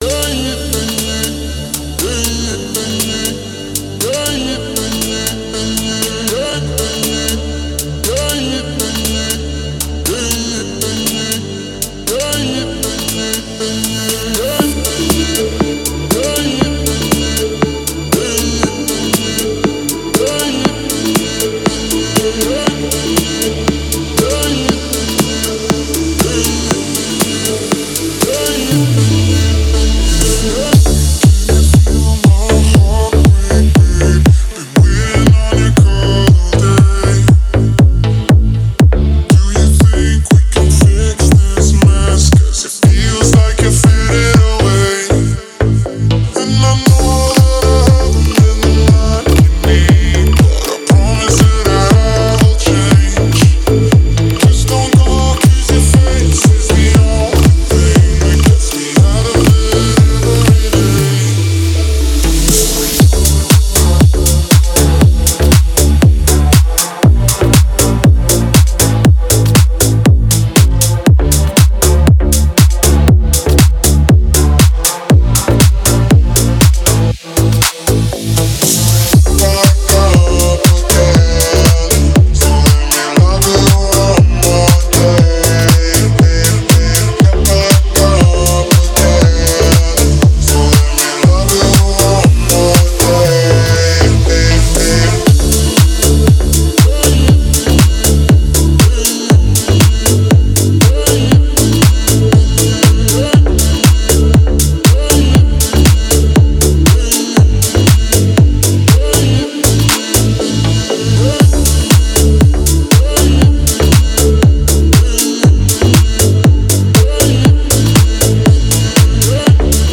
это трек в жанре электронная музыка